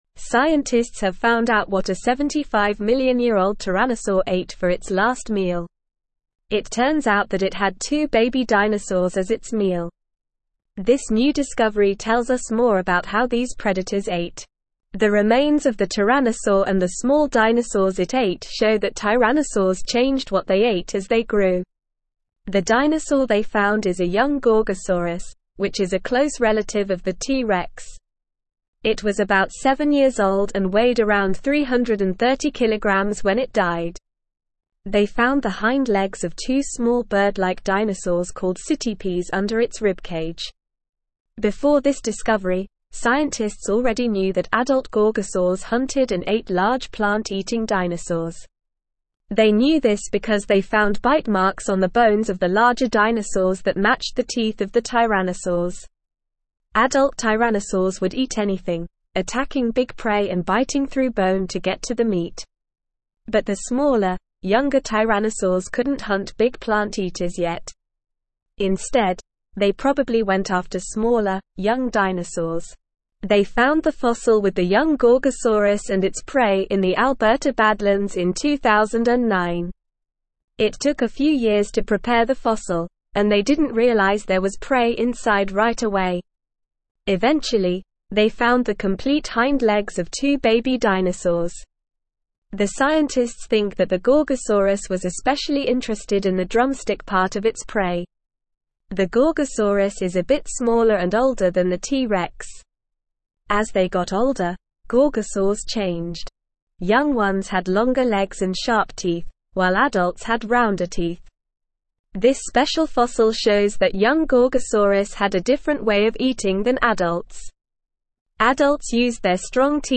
Normal
English-Newsroom-Upper-Intermediate-NORMAL-Reading-Ancient-Tyrannosaurs-Last-Meal-Two-Baby-Dinosaurs.mp3